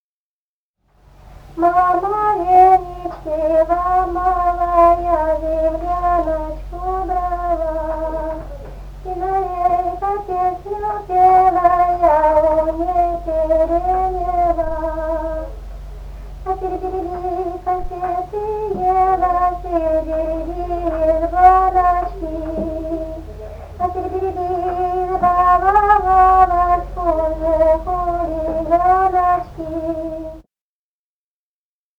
Русские народные песни Красноярского края.
«Мама венички ломала» (частушки). с. Тасеево Тасеевского района.